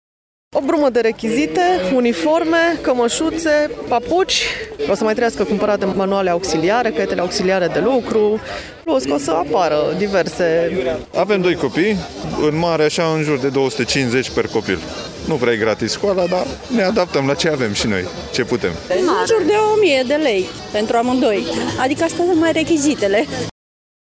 Cel puţin aşa spun părinţii din Braşov care au fost nevoiţi şi anul acesta să scoată din buzunare câteva sute de lei pentru rechizitele necesare copiilor, la şcoală:
vox-rechizite.mp3